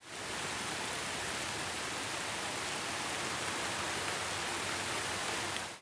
rain3.ogg